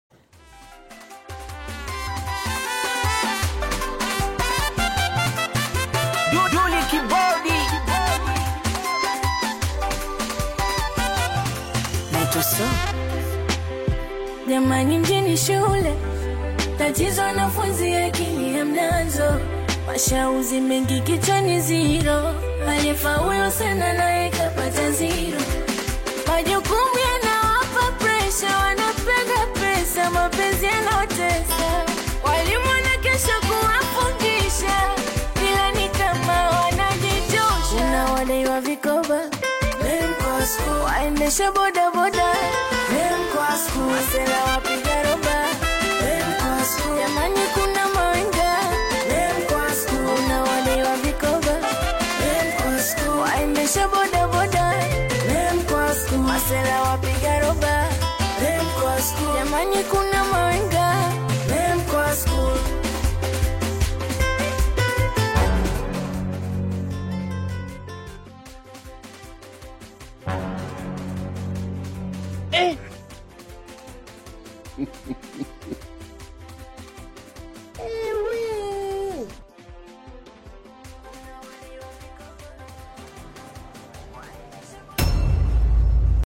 vibrant Tanzanian Singeli/Afro-urban soundtrack